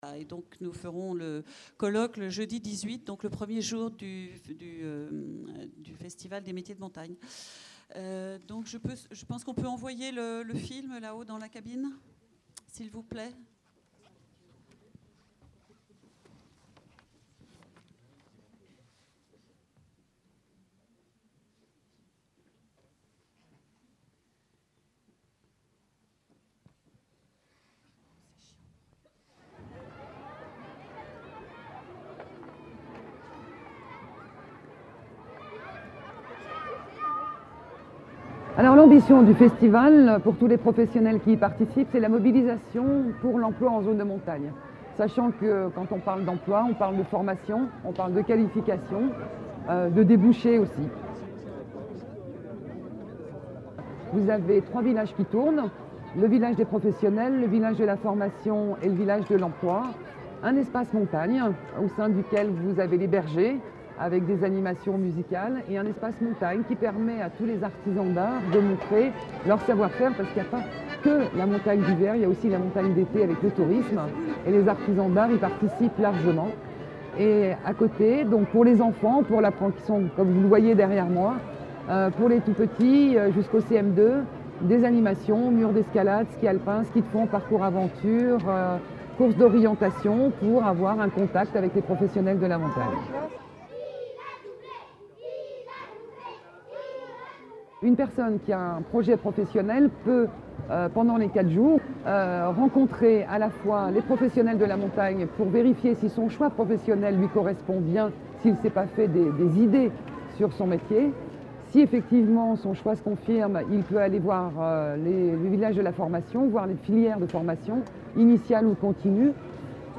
Les débats avec nos auteurs, intervenants du colloque organisé par Montanea à Chambéry le mardi 28 septembre 2010 sur le thème des métiers du tourisme, peuvent être écoutés ou téléchargés ici : Première partie (70,9 Mo) Deuxième partie (25,2 Mo) Troisième partie (35,9 Mo) Quatrième partie (48,6 Mo) a PROSPECTIVE Métiers de la montagne : le statut de la liberté ?